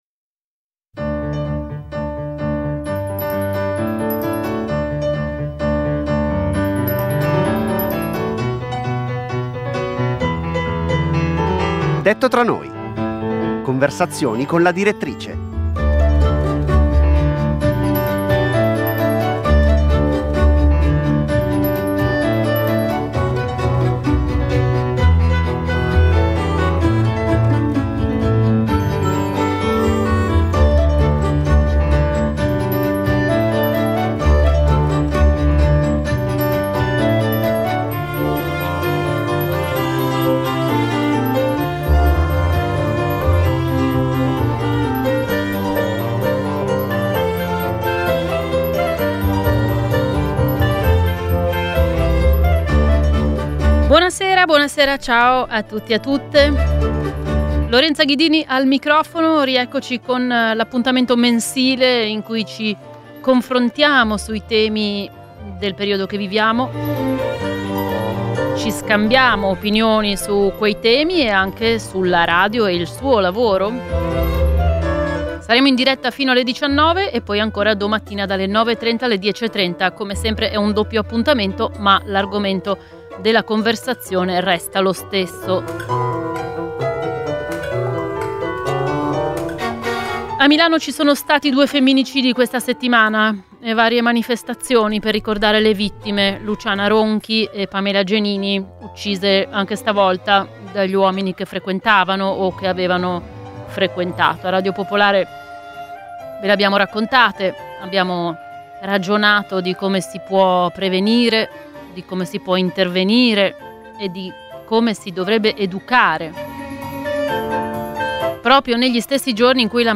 Conversazioni con la direttrice.